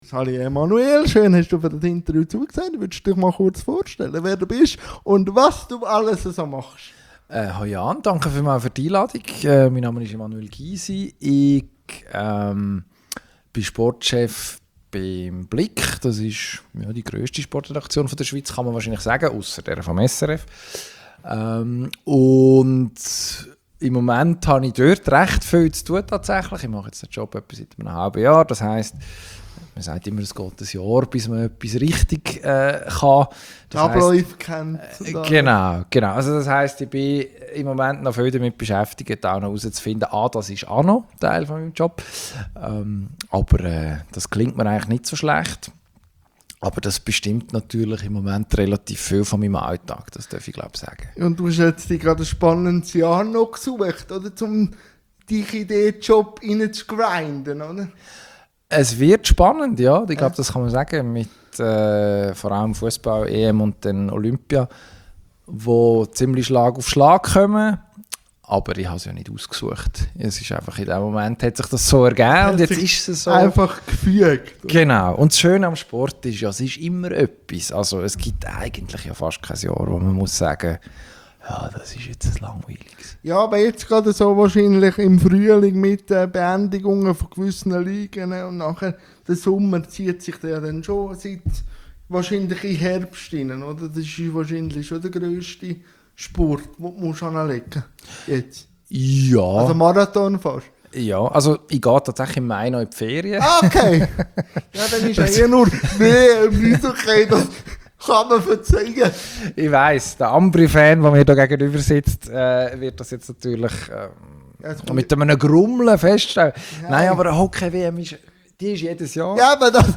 INTERVIEW-THEMEN